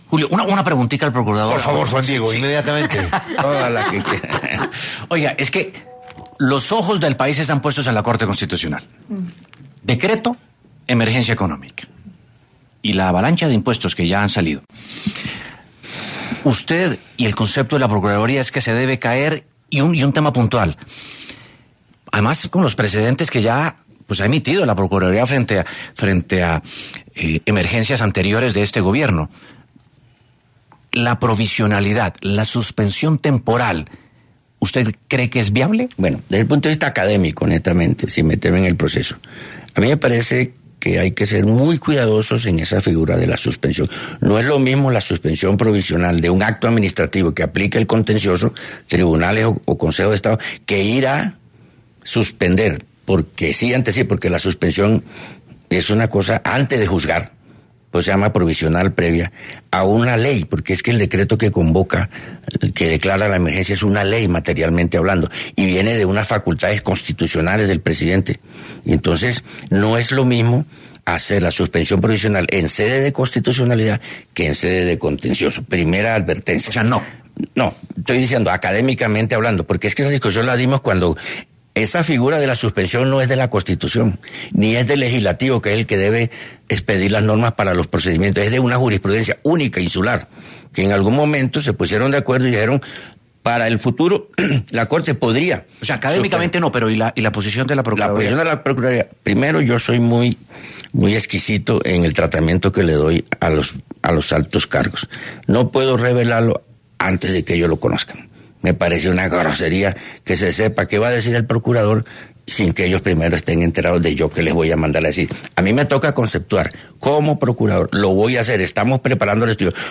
En entrevista con 6AM W de Caracol Radio, el Procurador General de la Nación, Gregorio Eljach, abordó la compleja cuestión de la viabilidad de la suspensión provisional del decreto de emergencia económica emitido por el gobierno de Gustavo Petro.
Procurador Gregorio Eljach habla en 6AM W